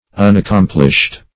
Unaccomplished \Un`ac*com"plished\, a.